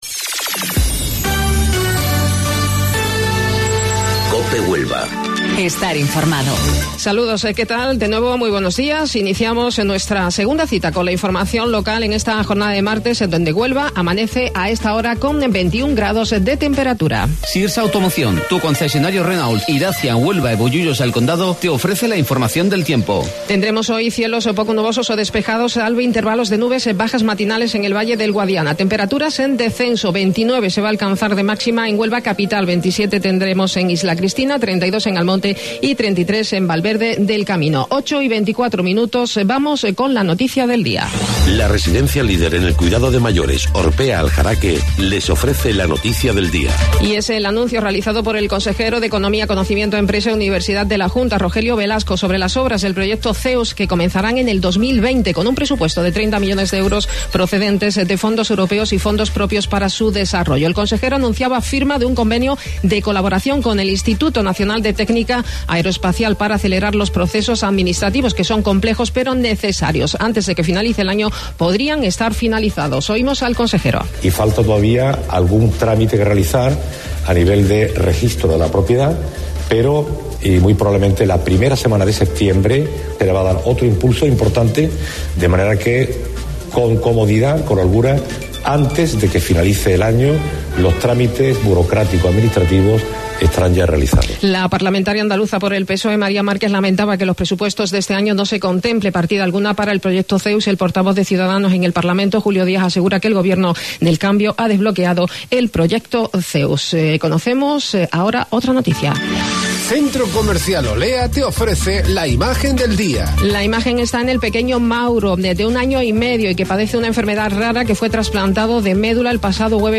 AUDIO: Informativo Local 08:25 del 2 de Julio